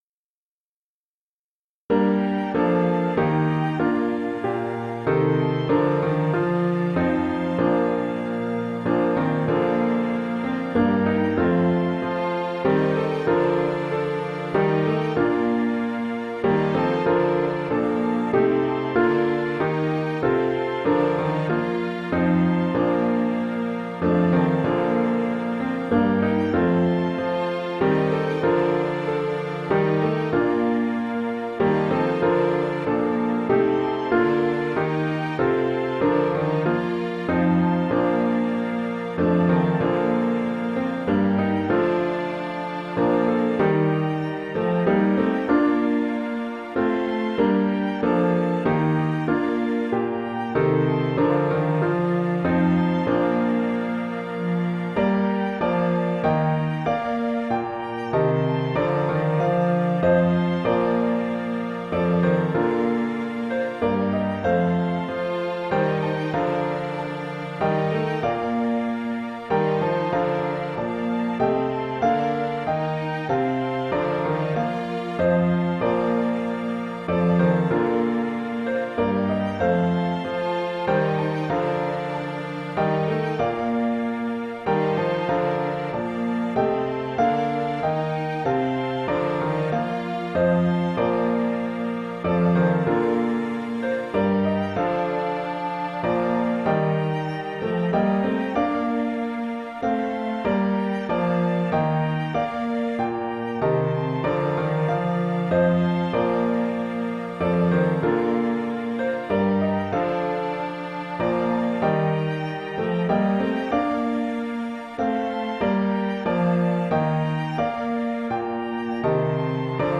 rehearsal recording
the-first-noel_key-of-Bb_2016VA.mp3